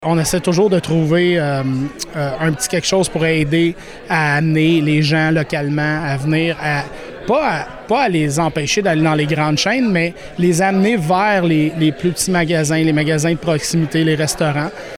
Cette initiative, qui est une première au Québec, a été annoncée en conférence de presse mercredi.